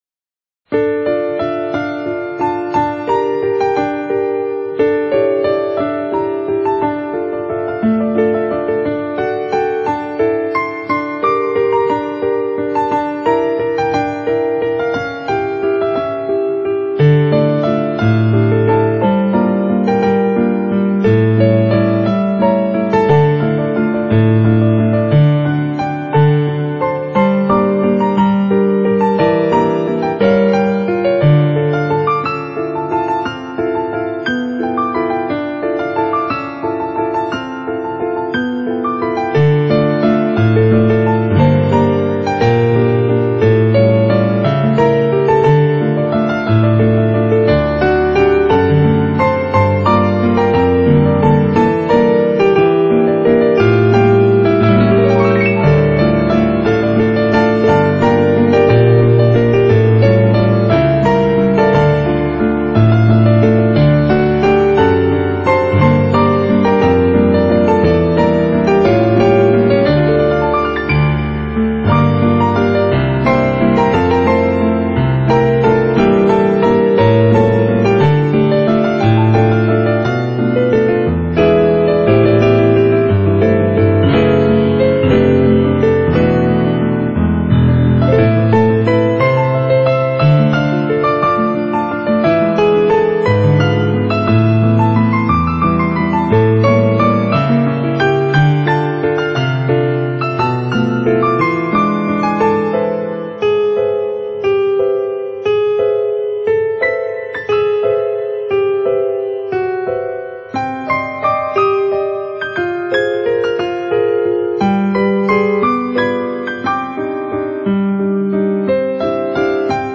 Genre : Ballroom Dance, Instrumental, Easy Listening